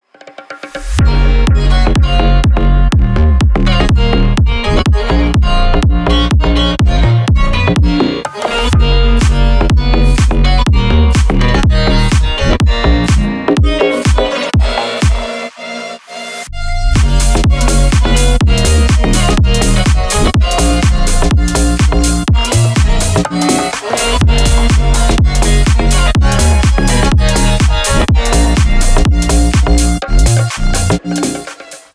• Качество: 320 kbps, Stereo
Поп Музыка
клубные
без слов